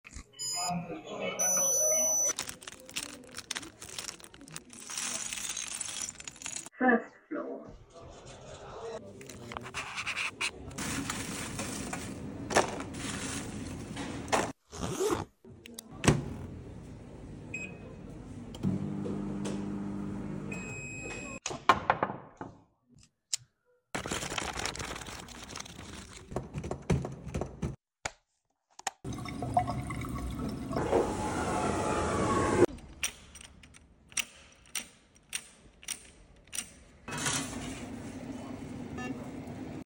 🎶 Campus ASMR to help sound effects free download
🎶 Campus ASMR to help sound effects free download By cu.coventry 0 Downloads 3 months ago 39 seconds cu.coventry Sound Effects About 🎶 Campus ASMR to help Mp3 Sound Effect 🎶 Campus ASMR to help you unwind during Mental Health Awareness Week 🎶 This video is reminder that even the smallest sounds can carry the greatest comfort.